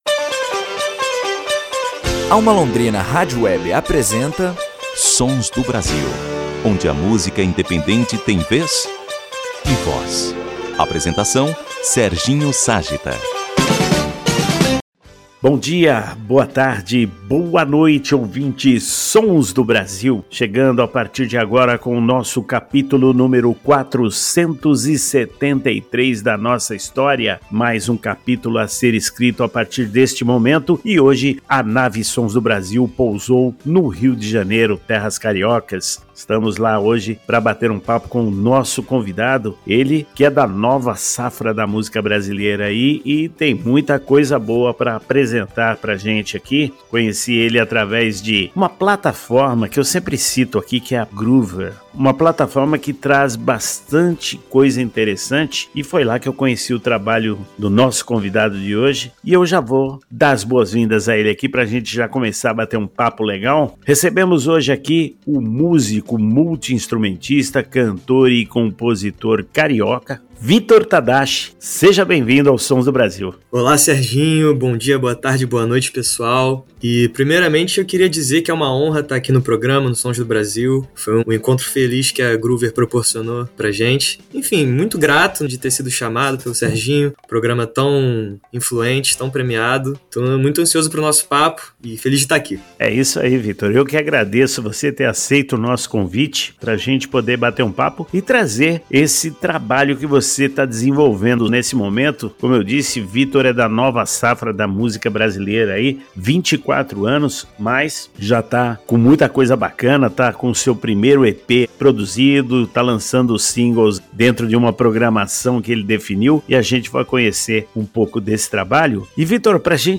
O SONS DO BRASIL nº 473 receberá para o bate papo